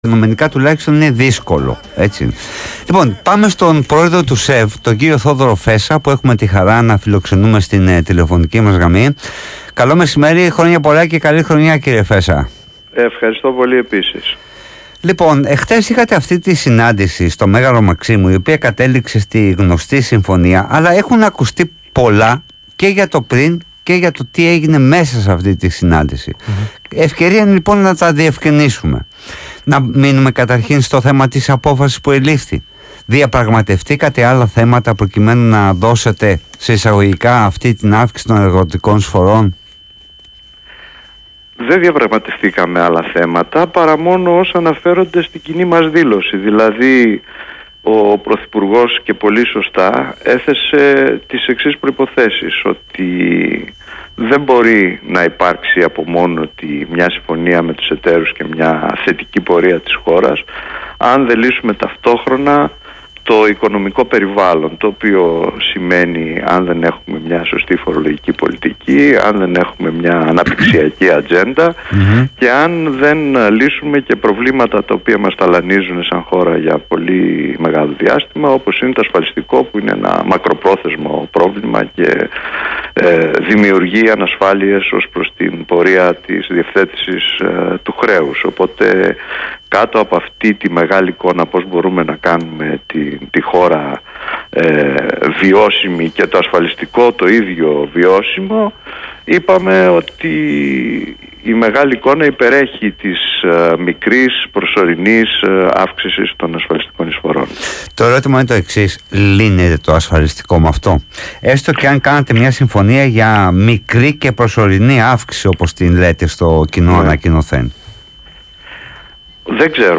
μιλά στον Ρ/Σ ΒΗΜΑ FM για το Ασφαλιστικό